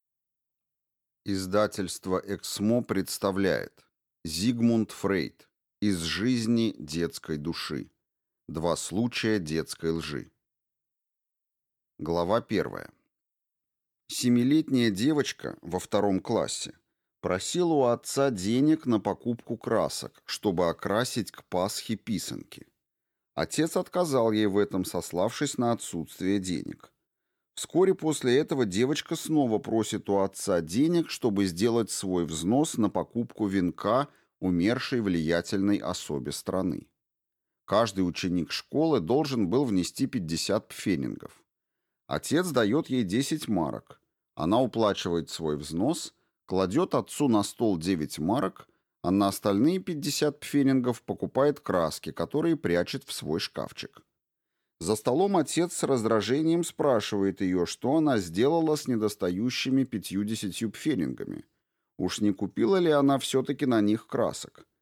Аудиокнига Из жизни детской души (Два случая детской лжи) | Библиотека аудиокниг